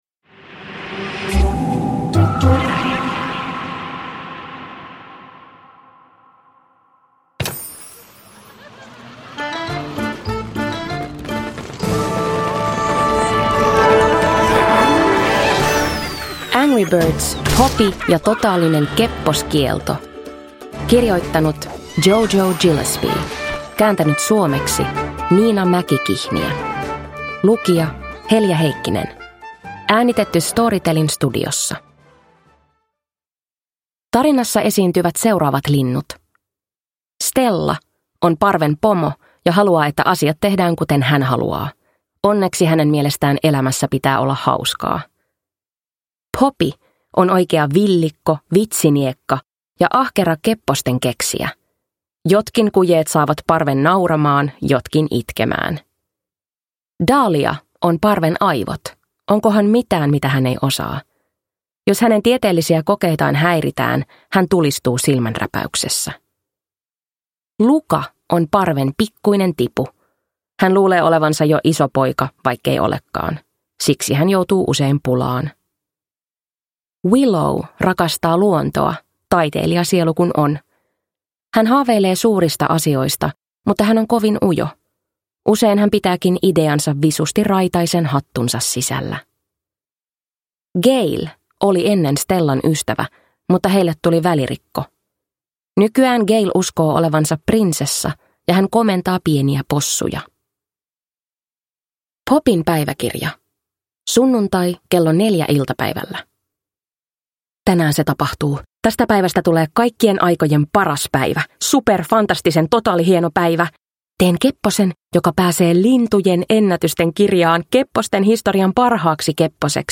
Angry Birds: Poppy ja totaalinen kepposkielto – Ljudbok – Laddas ner